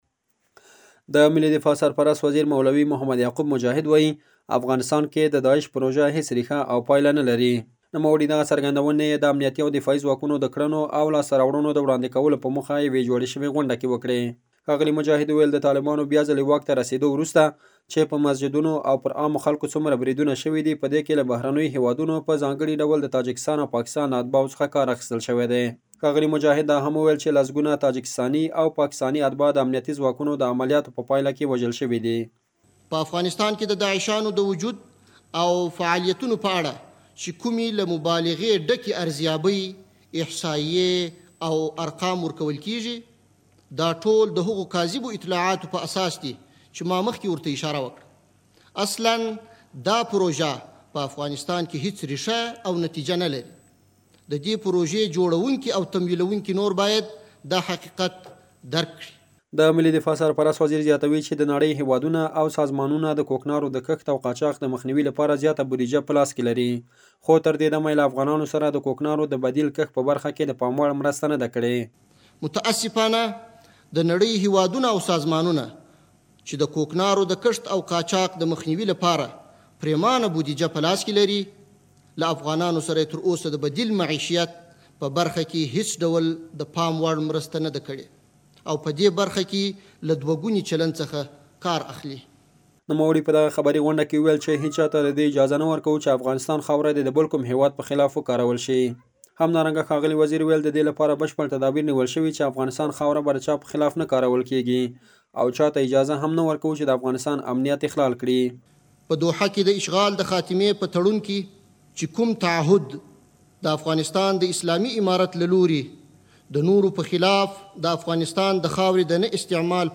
افغانستان کښې داعش ډله هیڅ جرړه او ریښه نلري نور راپور واورئ
زمونږ خبریال راپور راکړی د افغانستان د ملي دفاع سرپرست وزیر مولوي محمد یعقوب مجاهد وايي افغانستان کې د داعش پروژه هېڅ رېښه او پایله نه لري.